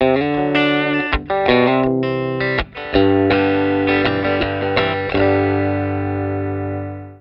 (WARNING- my T Bridge pickups are slightly microphonic on purpose.
T Bridge Pickup clean, through a 1950 Fender Deluxe:
TELE-BRIDGE-LICK-1-ML4.wav